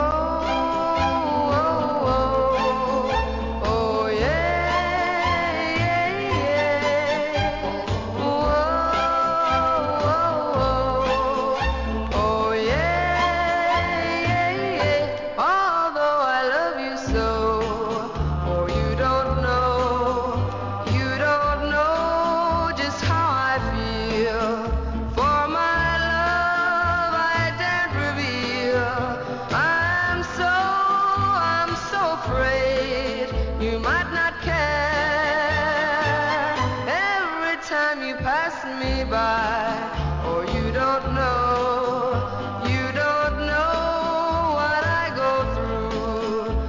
1. SOUL/FUNK/etc...
'60s イギリス人 女性シンガー大ヒット!!